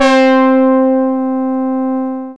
BRASS STYLE.wav